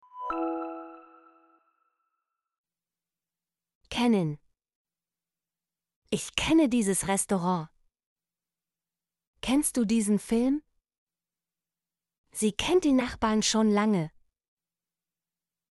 kennen - Example Sentences & Pronunciation, German Frequency List